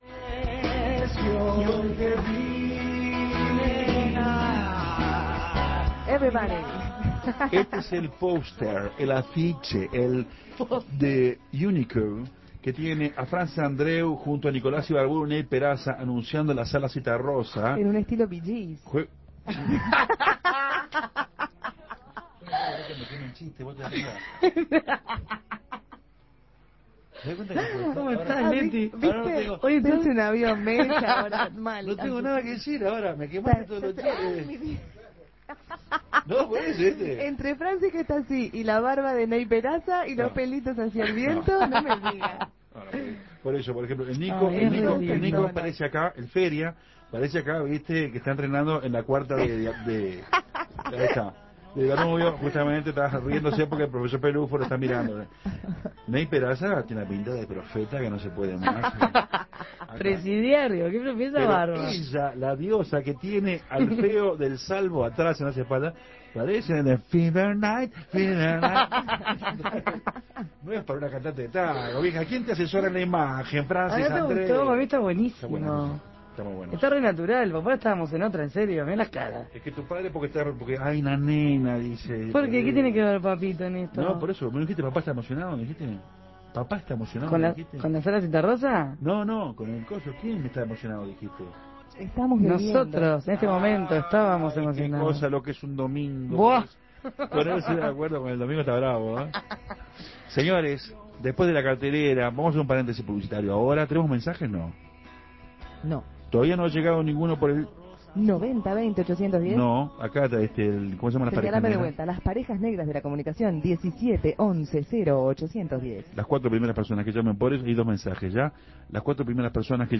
El tango, no sólo es cosa de hombres... el tango, no sólo es cosa de mayores...
haciendo fonoplatea